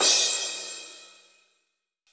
• Crash Cymbal Sample E Key 04.wav
Royality free drum crash tuned to the E note. Loudest frequency: 6647Hz
crash-cymbal-sample-e-key-04-TjX.wav